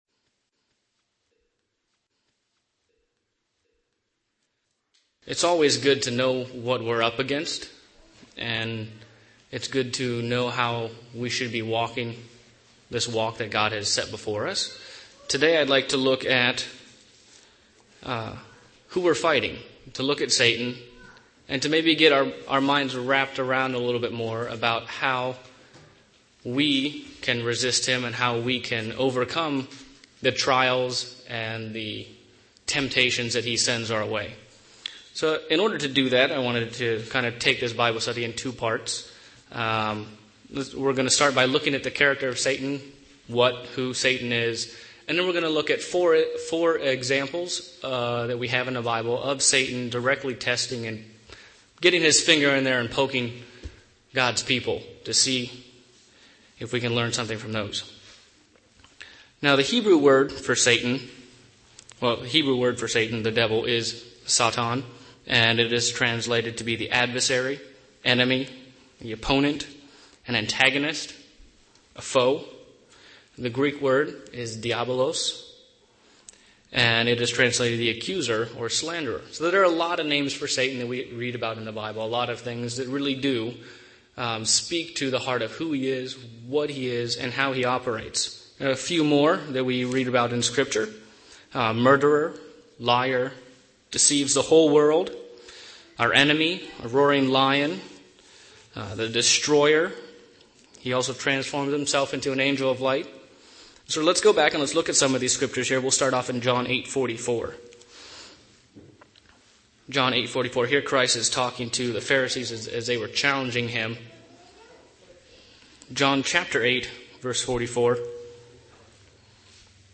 A Bible study on how to know your enemy and why it's important